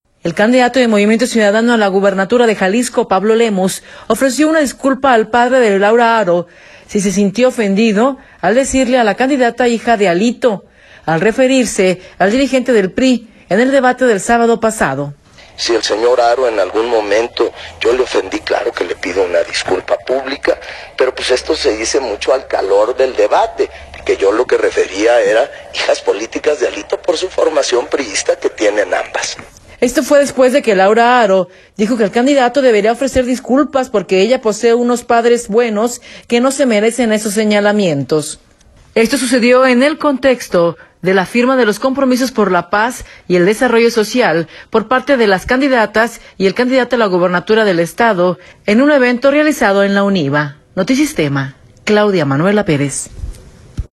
Esta fue después de que Laura Haro dijo que el candidato debería ofrecer disculpas porque ella posee unos padres buenos que no se merecen esos señalamientos. Esto sucedió en el contexto de la firma por los compromisos por la paz y el desarrollo social por parte de las candidatas y el candidato a la gubernatura del estado en un evento realizado en la UNIVA.